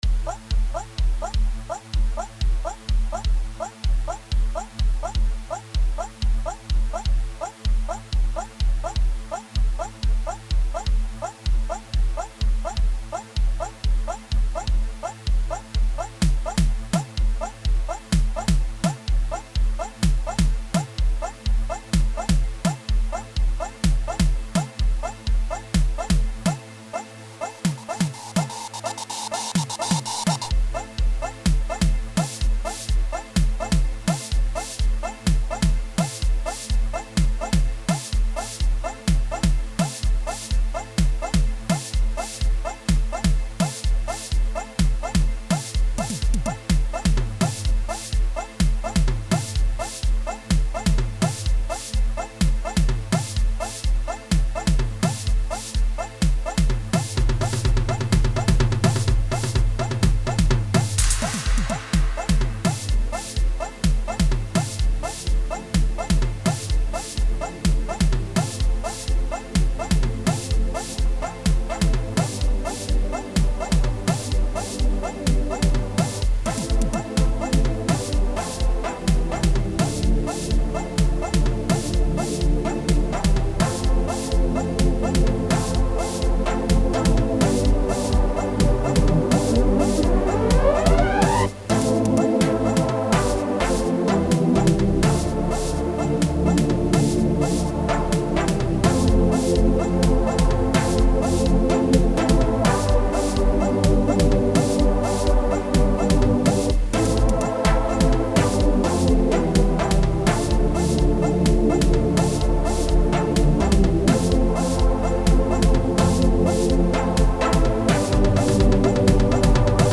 05:10 Genre : Gqom Size